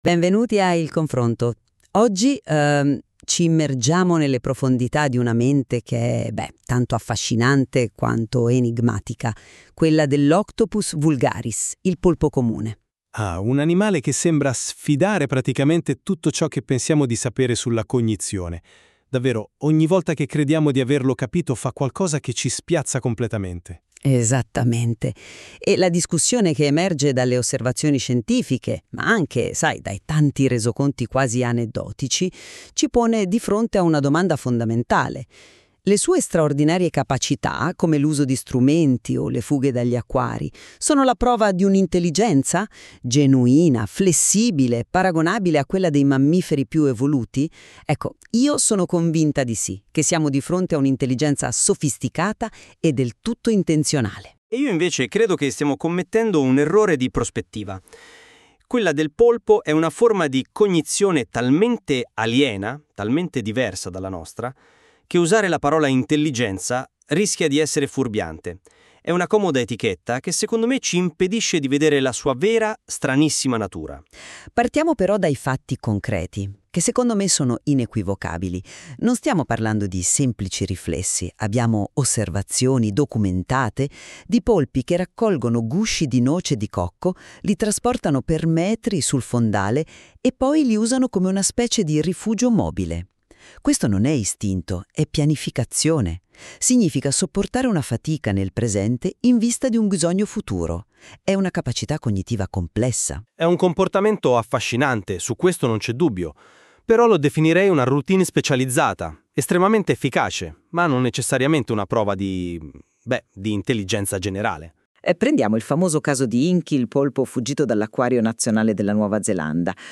I comportamenti "intelligenti" del polpo: dibattito tra scienziati ed esperti.
Ascolta il Dibattito: Intelligenza o Istinto?
POLPO-INTELLIGENZA_DIBATTITO_STREAMING.mp3